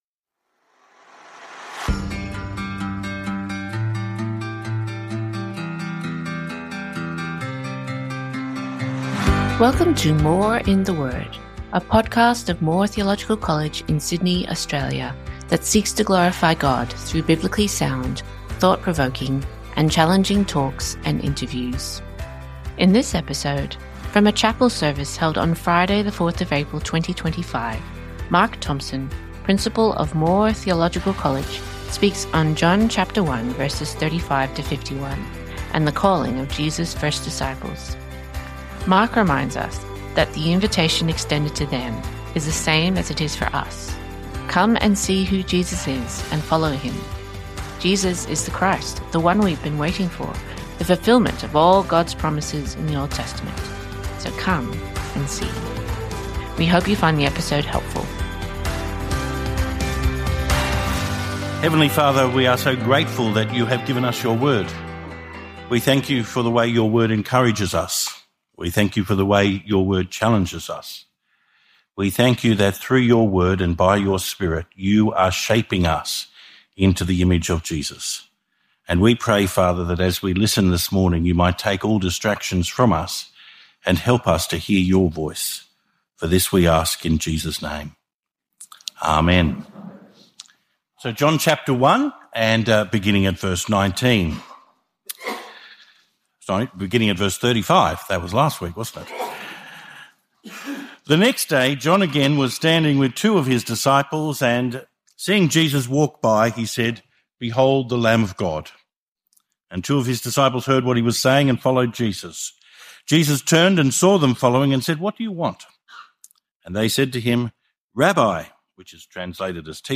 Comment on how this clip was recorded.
from a chapel service held on Friday 4 April 2025